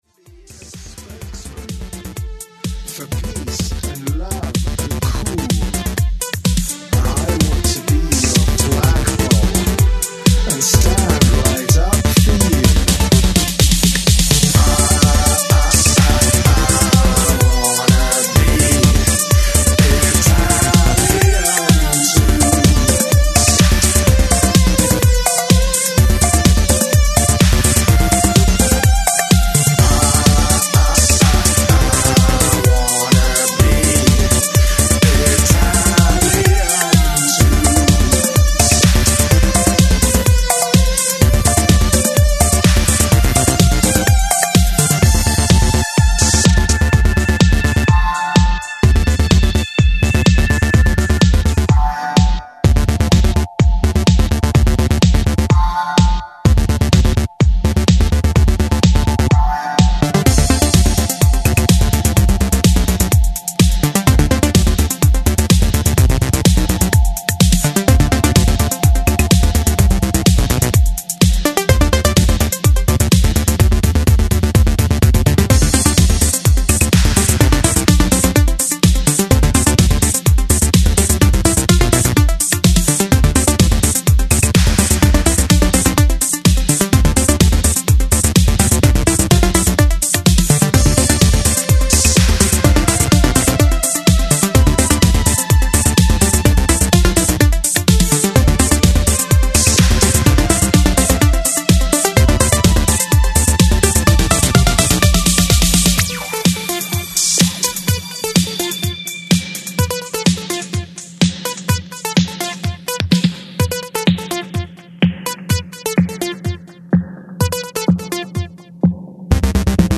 electro italo pop